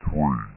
Three! Sound Effect